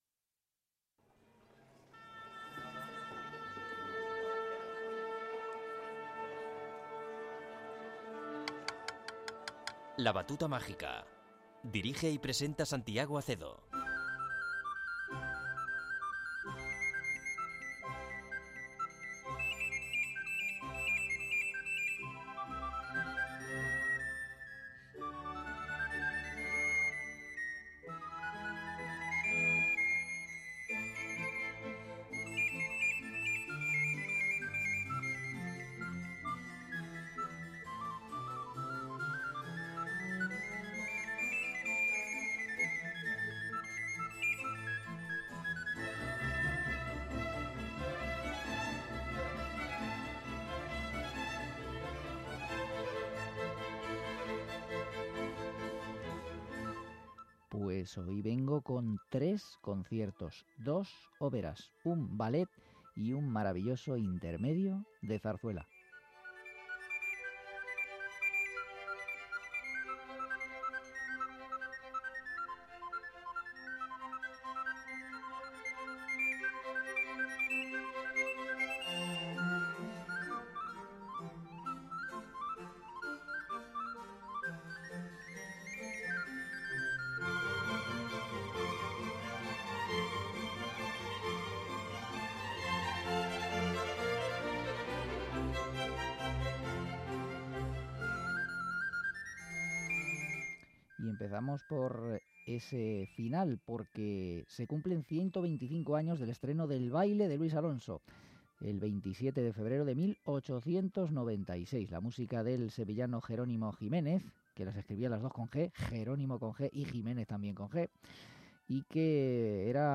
con 2 Oboes
para Violonchelo